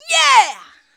YEAH.wav